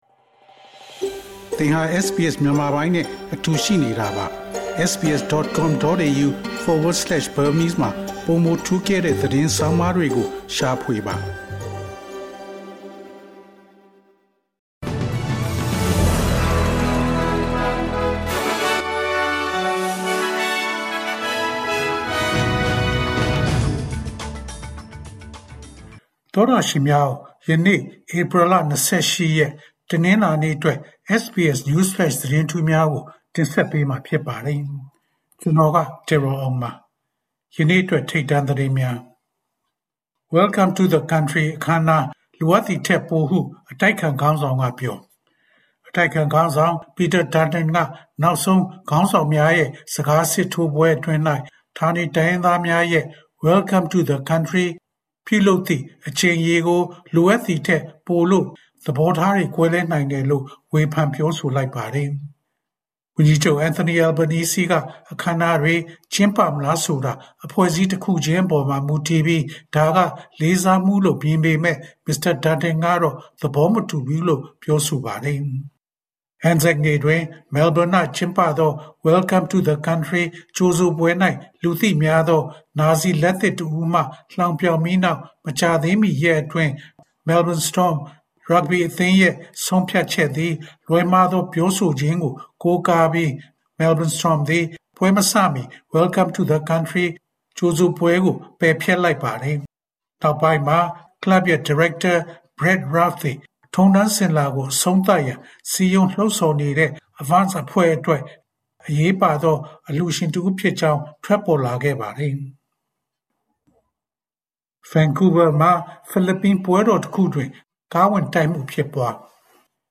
ALC: ၂၀၂၅ ခုနှစ် ဧပြီ ၂၈ ရက်, SBS Burmese News Flash သတင်းများ။
SBS မြန်မာ ၂၀၂၅ ခုနှစ် ဧပြီ ၂၈ ရက် နေ့အတွက် News Flash သတင်းများ။